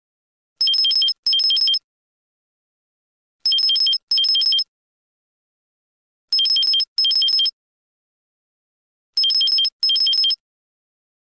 Забавная мелодия смс для вашего телефона